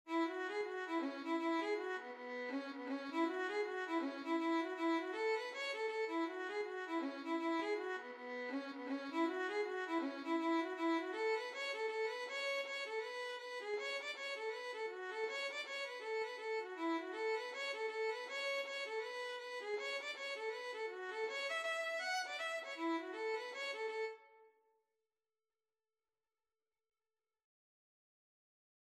4/4 (View more 4/4 Music)
Violin  (View more Intermediate Violin Music)
Reels